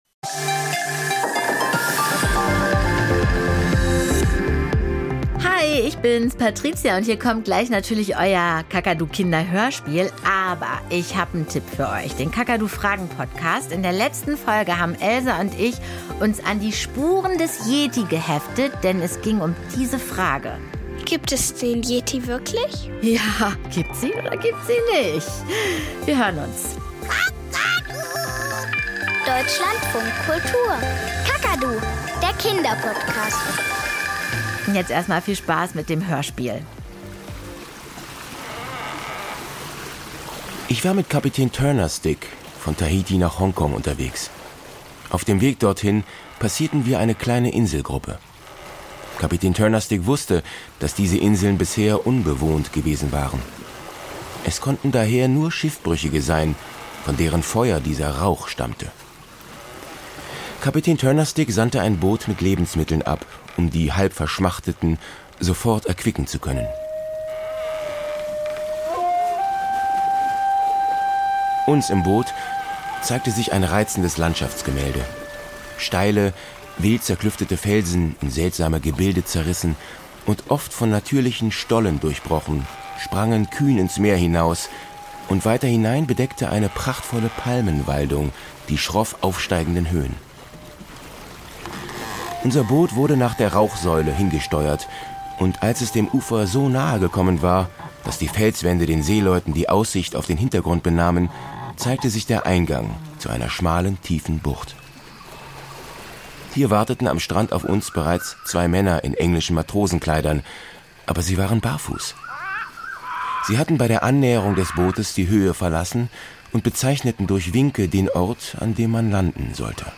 Kinderhörspiel und Geschichten - Im Taifun